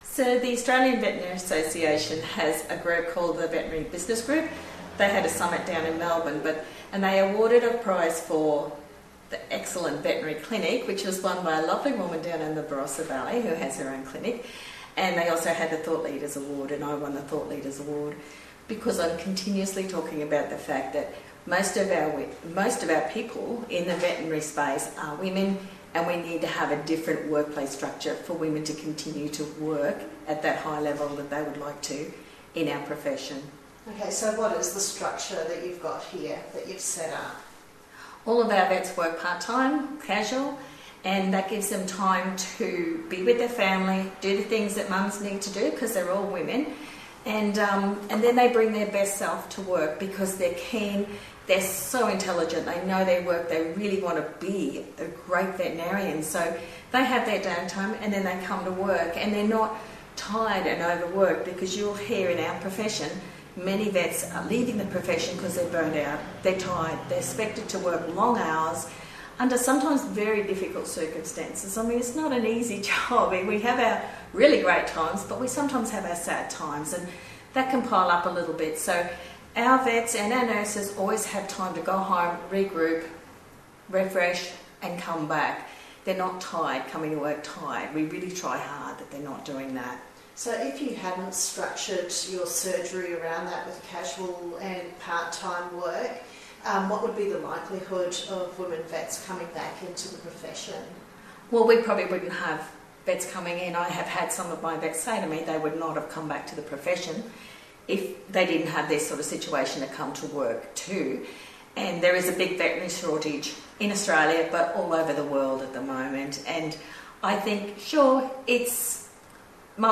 INTERVIEWS | Smooth Operating Vet